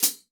14HK FOOT.wav